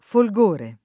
folgore [ fol g1 re ]